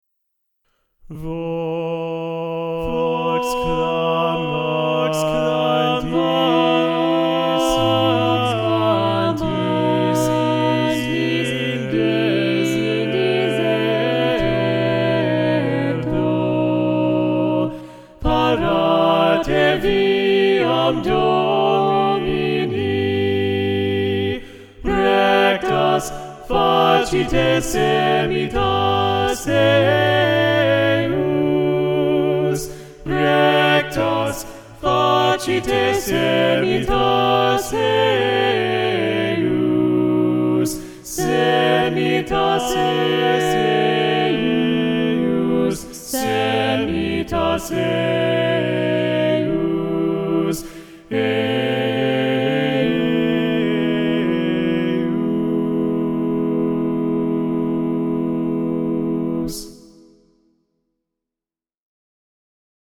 for Three Voices